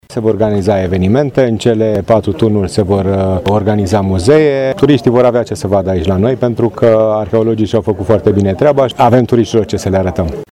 După inaugurarea oficială a obiectivului, turiștii vor fi întâmpinați cu multe surprize la Cetatea Feldioara, așa cum ne-a spus primarul comunei Feldioara, Sorin Taus: